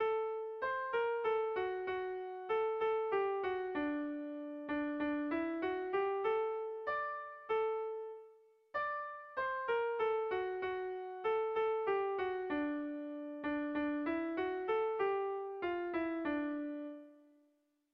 Bertso melodies - View details   To know more about this section
A1A2